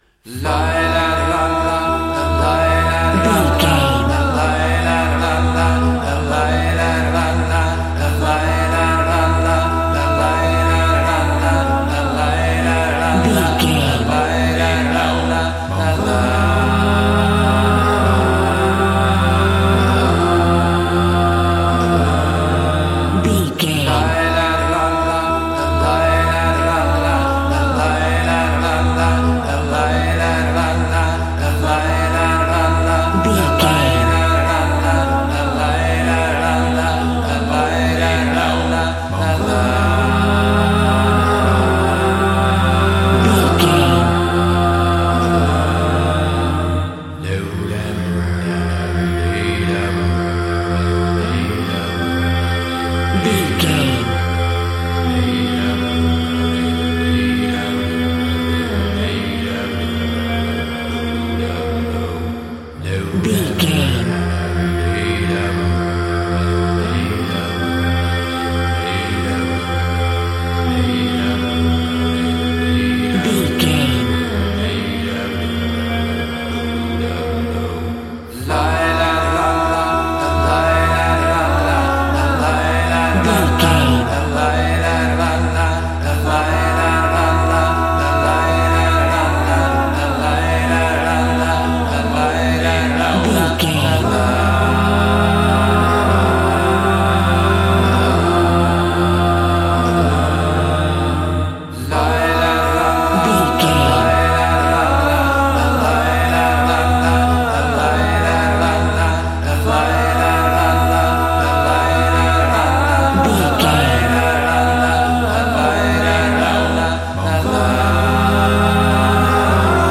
Aeolian/Minor
groovy